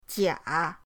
jia3.mp3